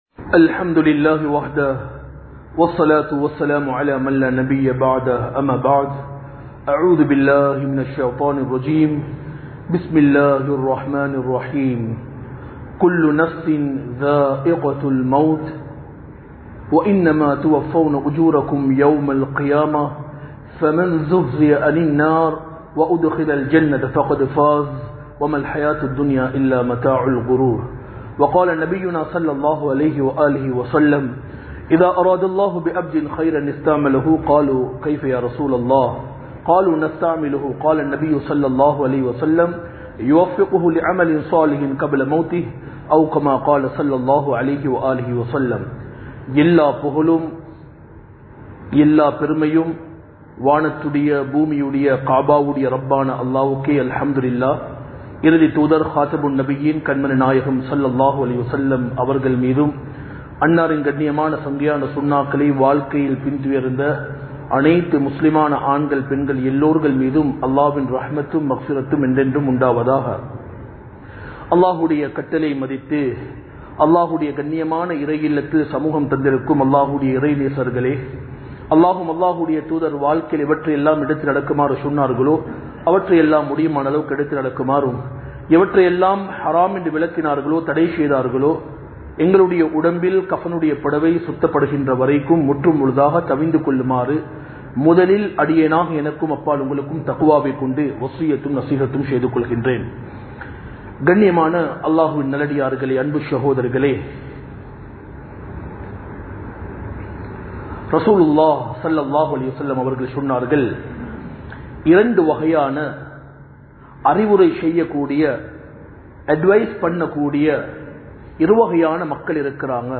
கப்ரில் கேட்கப்படும் கேள்விகள் | Audio Bayans | All Ceylon Muslim Youth Community | Addalaichenai
Colombo 04, Majma Ul Khairah Jumua Masjith (Nimal Road)